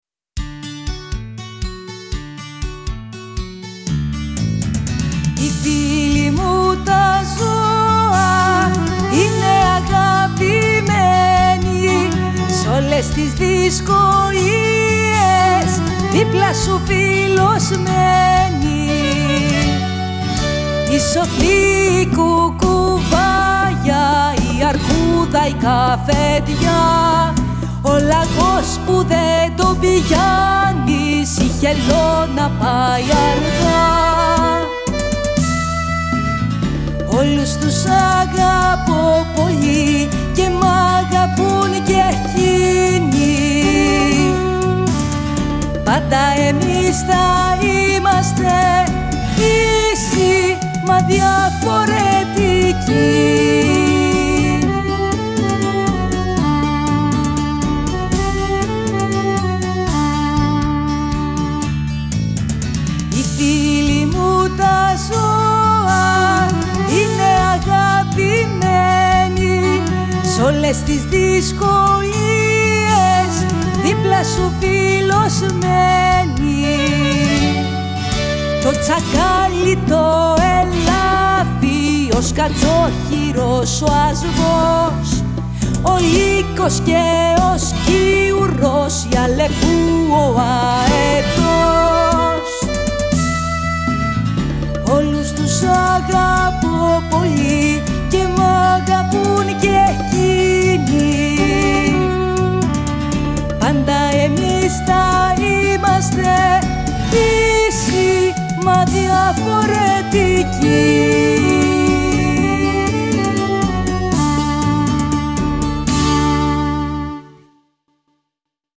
στο studio FREQ